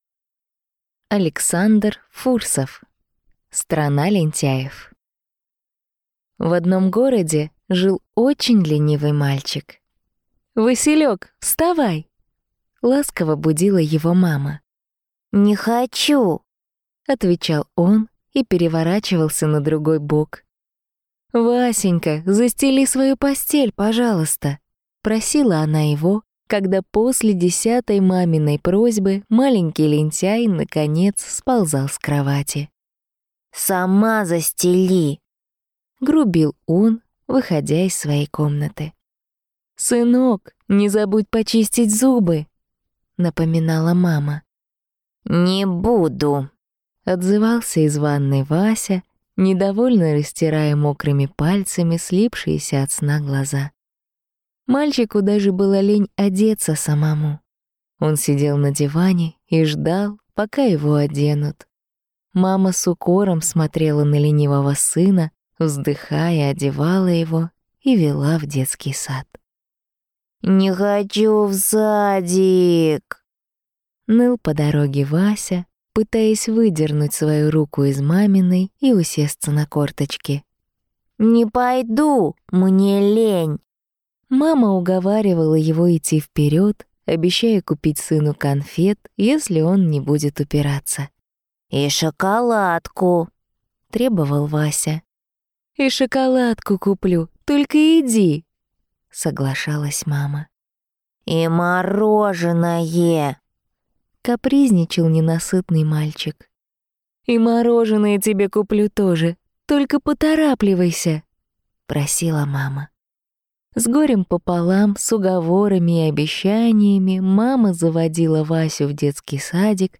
Аудиокнига Страна лентяев | Библиотека аудиокниг